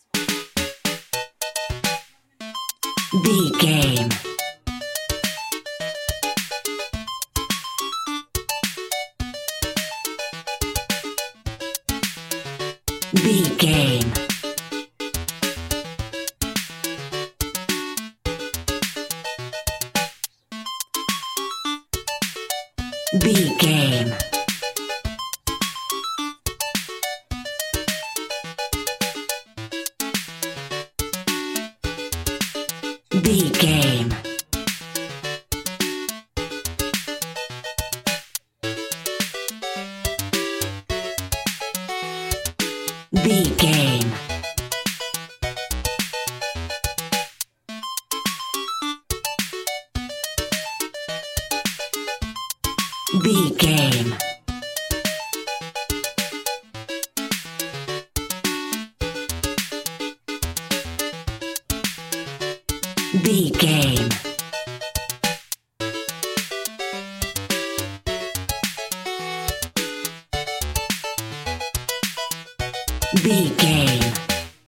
Ionian/Major
bouncy
bright
cheerful/happy
funky
groovy
lively
playful
uplifting
synthesiser
drum machine